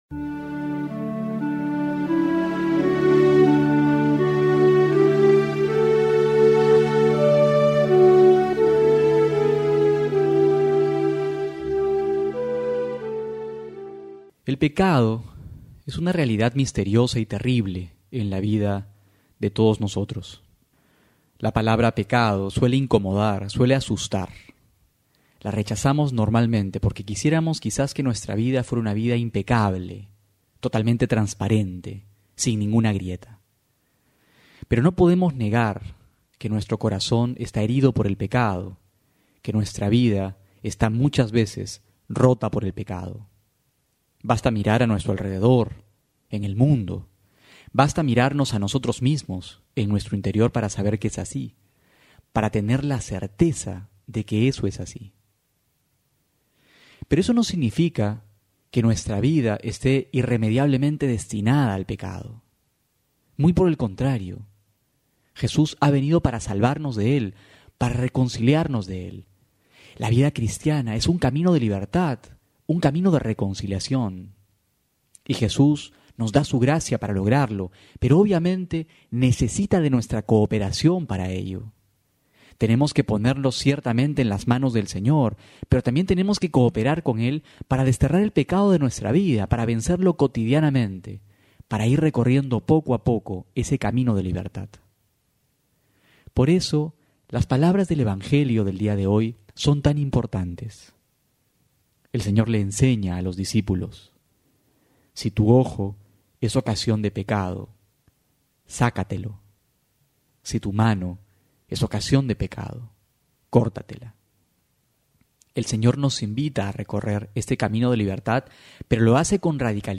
Homilía para hoy: Lucas 9,22-25
febrero23-12homilia.mp3